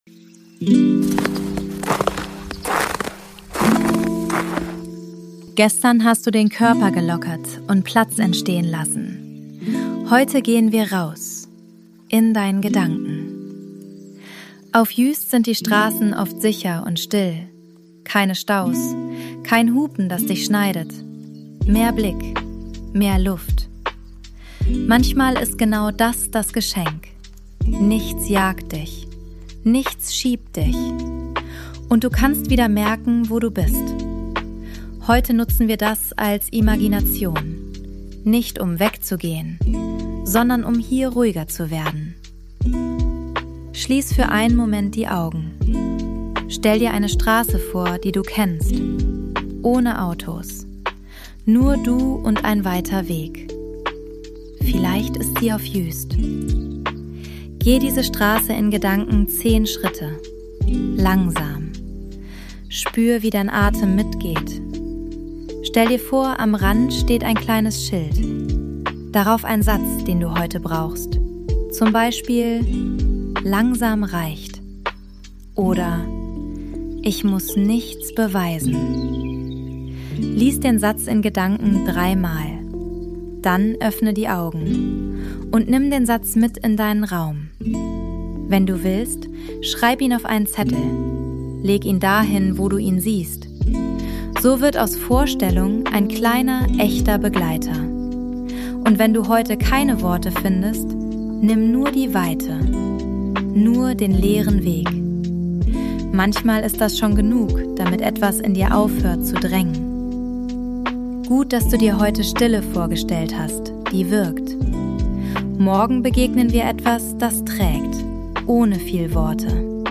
Juist Sounds & Mix: ElevenLabs und eigene Atmos